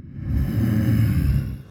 Id_scream2.ogg